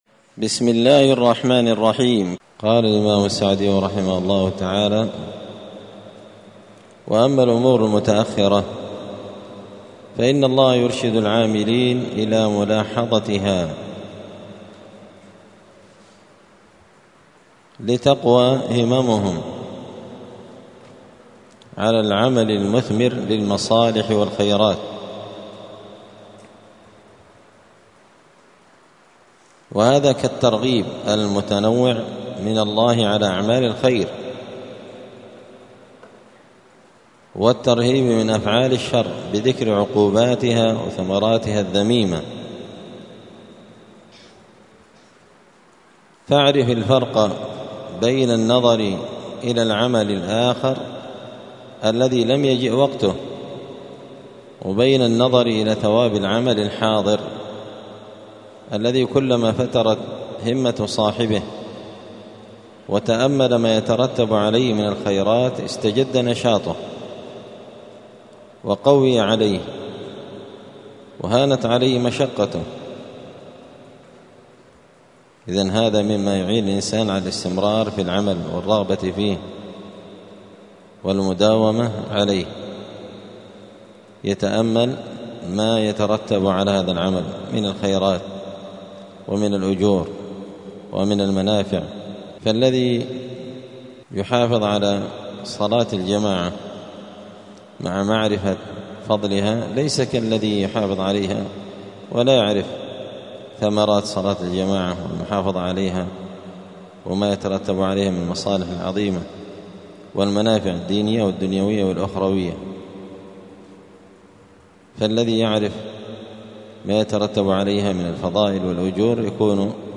دار الحديث السلفية بمسجد الفرقان قشن المهرة اليمن
الدروس اليومية